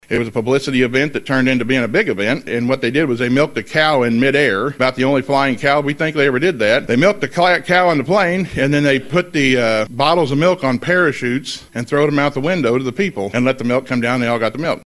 Bismarck Mayor Seth Radford explained the significance of the event, highlighting the historical moment when Elmfarm Ollie became the first cow to fly in an airplane and be milked mid-flight.